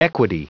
Prononciation du mot equity en anglais (fichier audio)
Prononciation du mot : equity